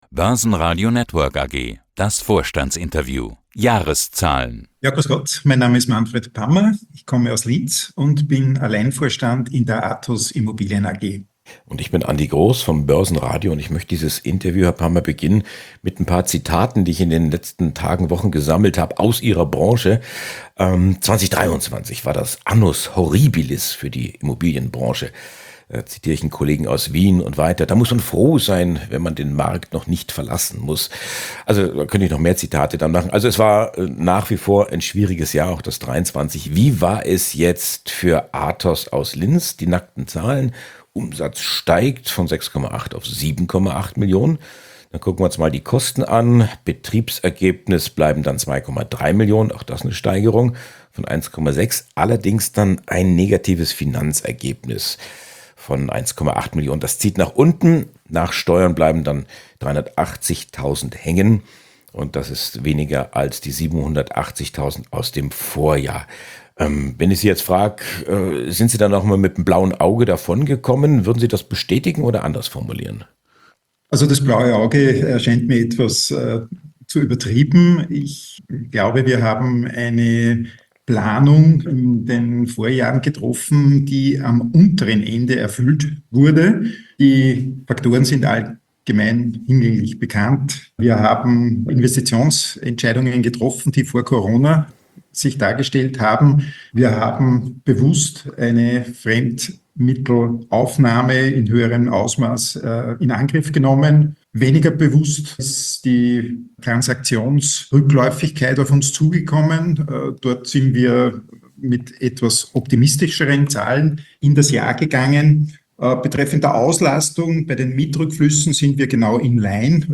In diesen Podcasts hören Sie Interviews rund um die Wiener Börse und den österreichischen Kapitalmarkt.